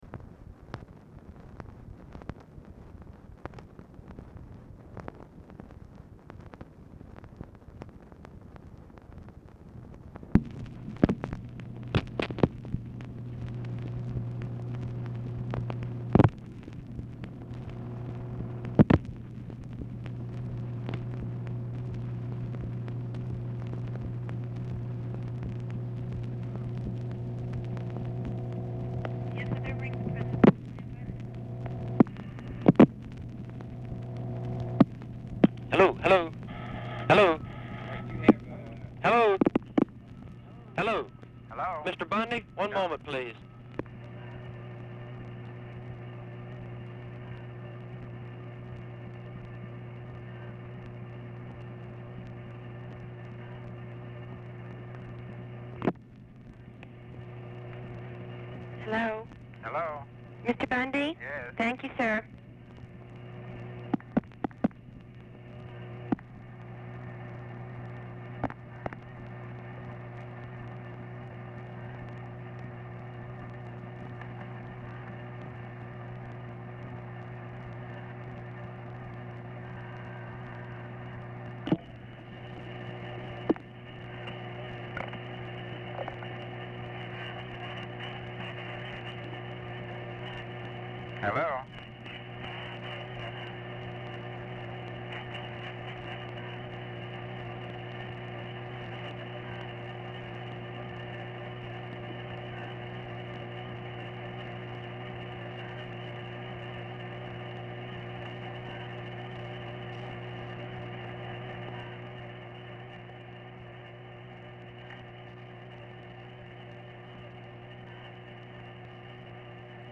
HIGH-PITCHED WHINE OCCURS DURING CALL
Format Dictation belt
Specific Item Type Telephone conversation Subject Communist Countries Defense Diplomacy Latin America